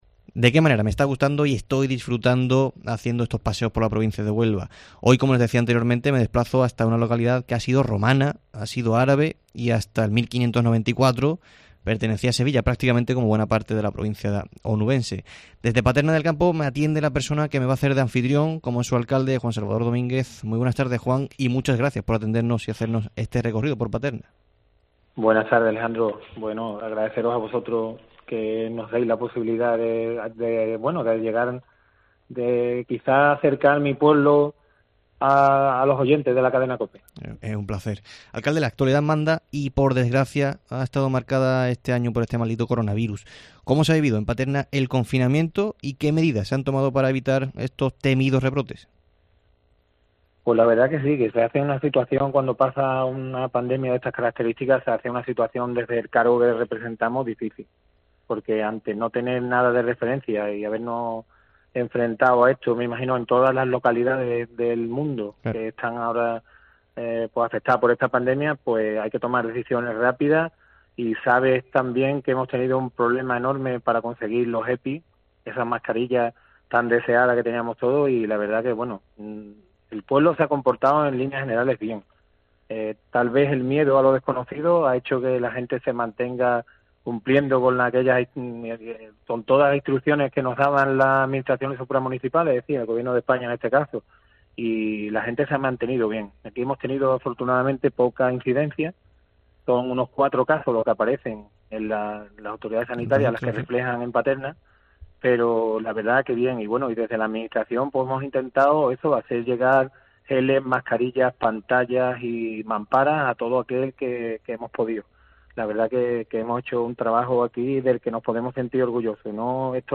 En este 9 de julio nos hemos desplazado hasta Paterna del Campo en nuestros paseos por la provincia de Huelva donde su alcalde, Juan Salvador Domínguez, nos ha hecho de anfitrión.